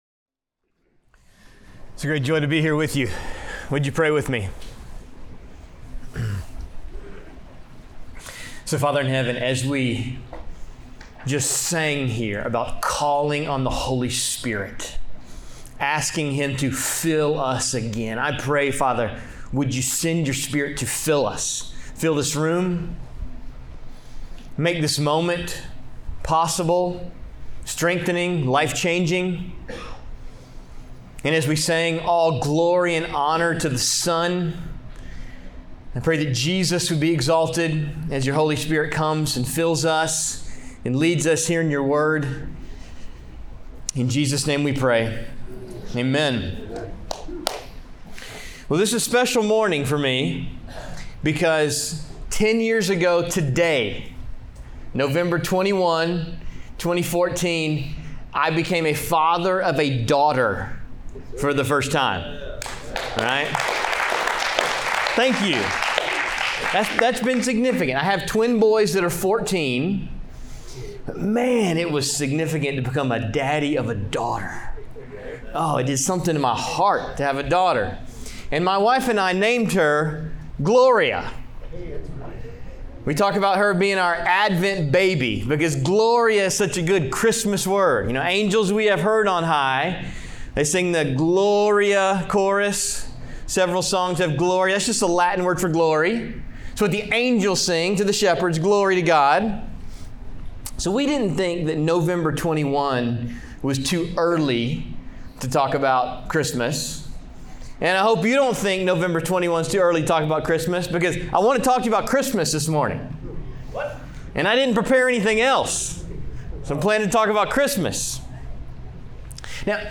Minnesota Adult and Teen Challenge | Minneapolis